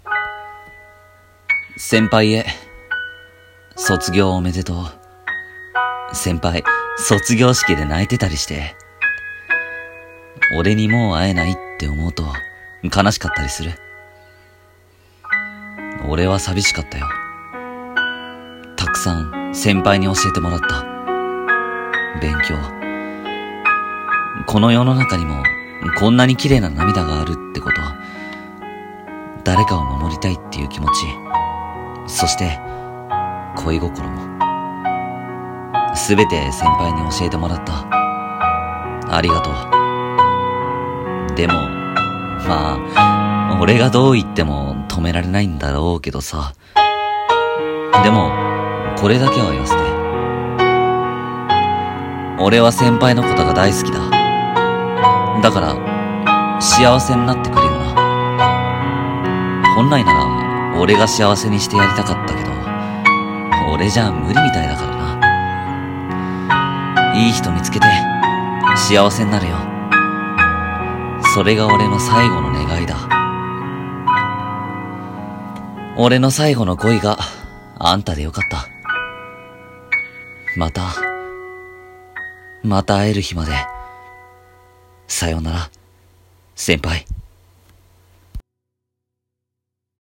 声劇 _先輩への想い_